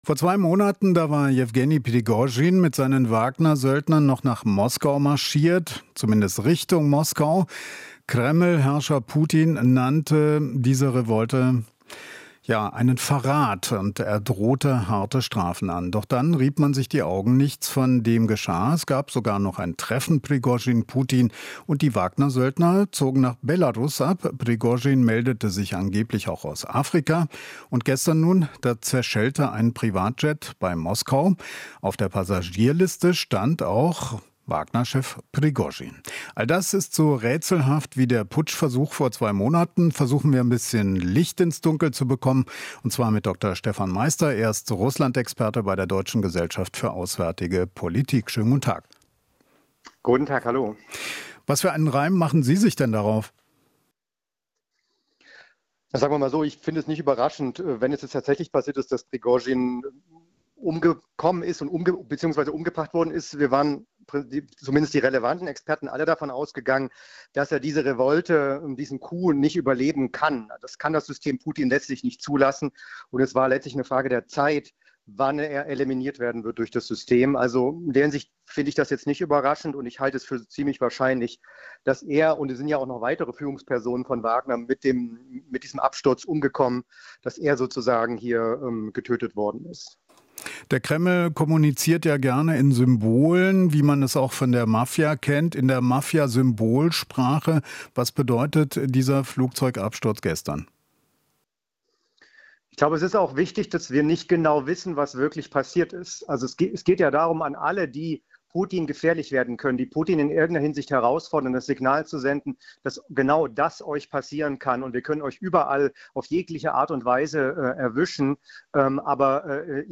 Interview - Politologe: "System Putin konnte Überleben Prigoschins nicht zulassen"